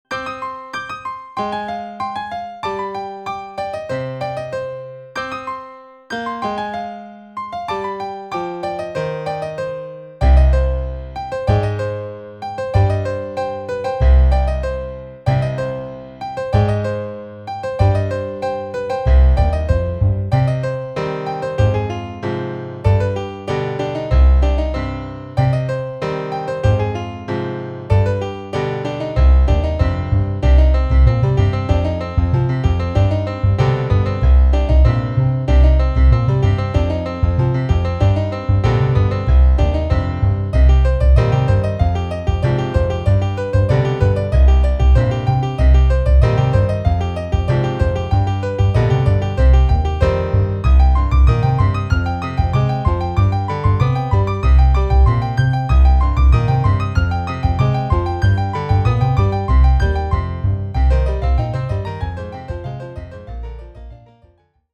piano_2_bass_demo.mp3